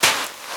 STEPS Sand, Walk 28.wav